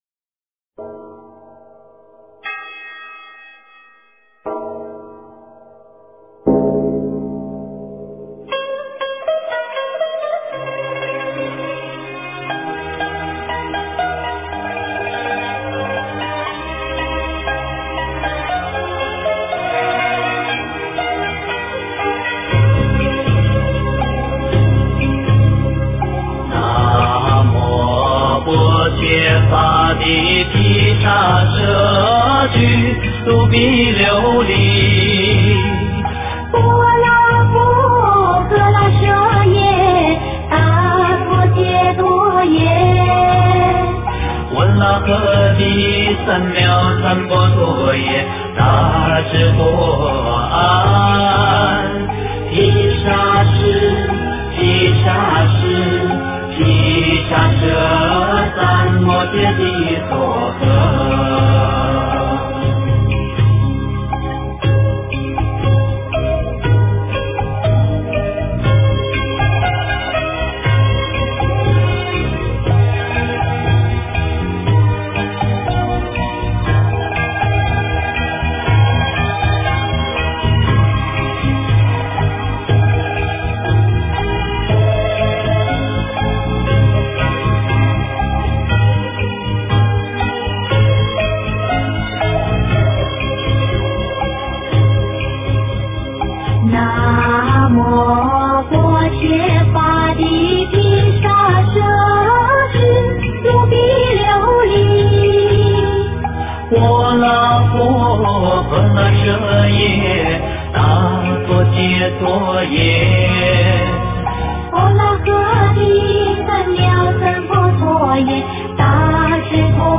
药师佛灌顶真言--佛教歌曲
药师佛灌顶真言--佛教歌曲 真言 药师佛灌顶真言--佛教歌曲 点我： 标签: 佛音 真言 佛教音乐 返回列表 上一篇： 六字大明咒--佚名 下一篇： 韦陀尊天菩萨--贵族乐团 相关文章 大慈大悲观世音--唱经给你听 大慈大悲观世音--唱经给你听...